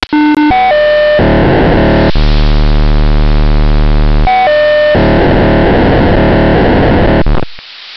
File:929.060 MHZ POCSAG.wav - Signal Identification Wiki